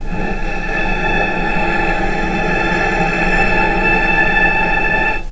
healing-soundscapes/Sound Banks/HSS_OP_Pack/Strings/cello/sul-ponticello/vc_sp-A5-pp.AIF at bf8b0d83acd083cad68aa8590bc4568aa0baec05
vc_sp-A5-pp.AIF